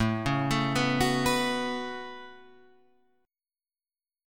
A 6th Add 9th